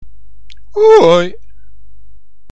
Oewoi is een soort jodelgeluid. Het werd vroeger gebruikt om elkaars aandacht te trekken over grote afstanden.